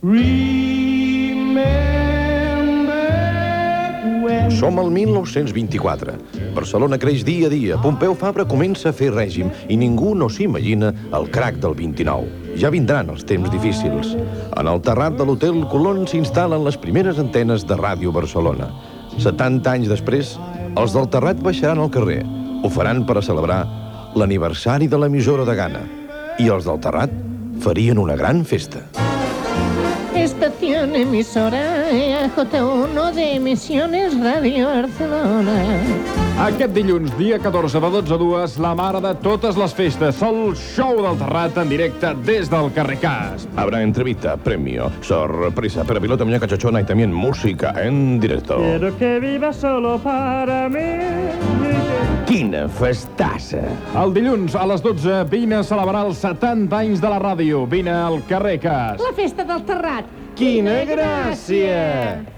Promoció del programa especial del Terrat amb motiu dels 70 anys de Ràdio Barcelona